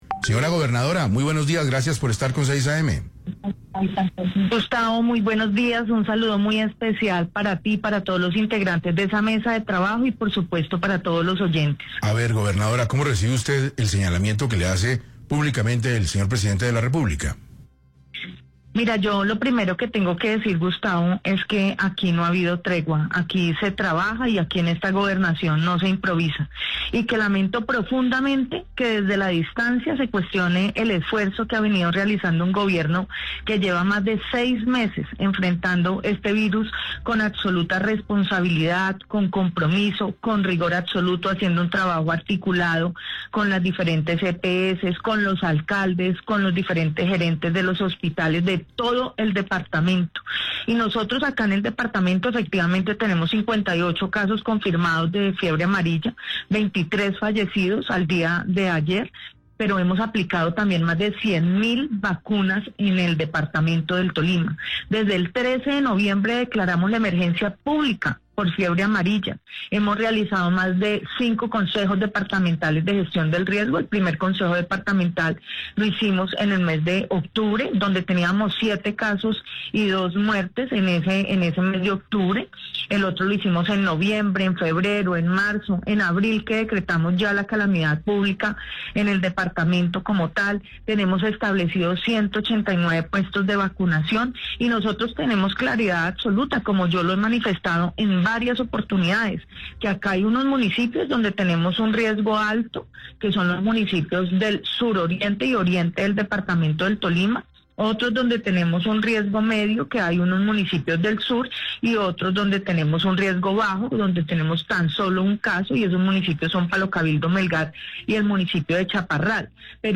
Luego del intercambio por redes sociales entre la gobernadora del Tolima, Adriana Magali Matiz y el presidente Gustavo Petro, luego de que este último acusara a la mandataria de no girar recursos para enfrentar la fiebre amarilla en el departamento, la mandataria local aseguró en 6AM de Caracol Radio que el presidente miente.